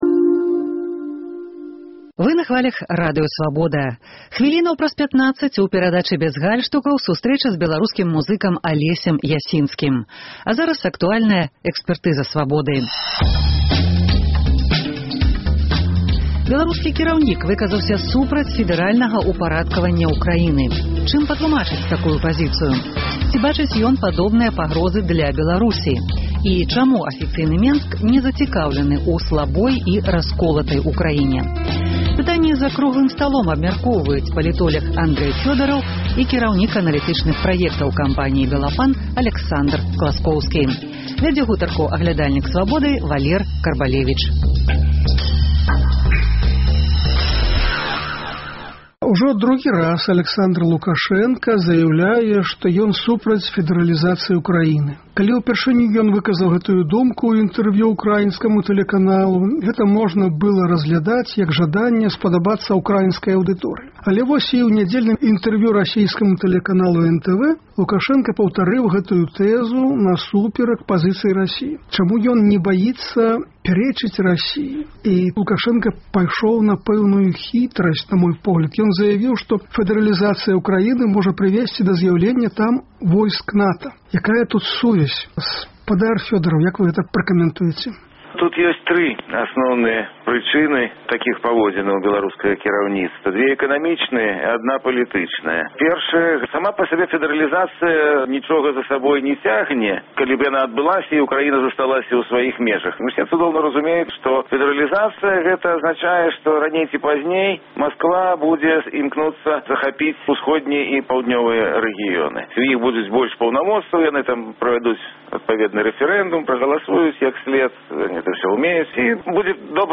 Чаму афіцыйны Менск не зацікаўлены ў слабой і расколатай Украіне? Гэтыя пытаньні абмяркоўваюць за круглым сталом «Экспэртызы «Свабоды»